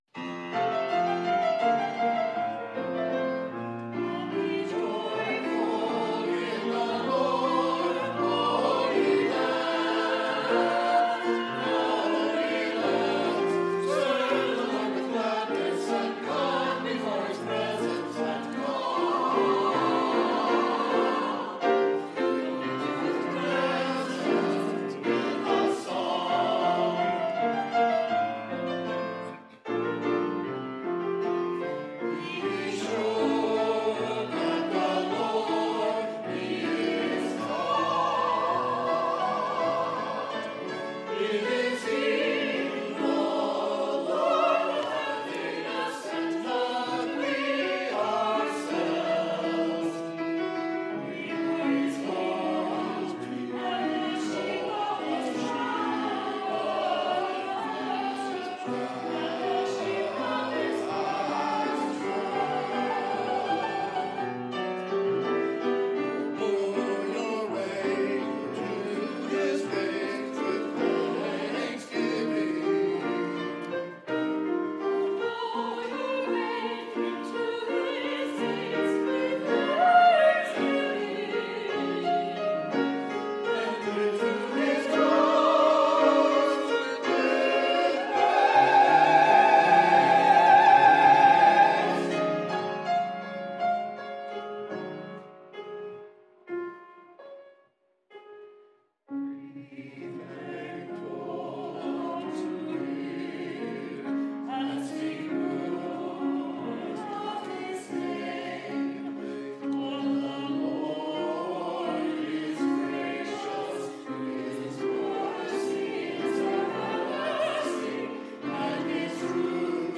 O Be Joyful in the Lord (Choir)
o_be_joyful_in_the_lord_choir_mixed.mp3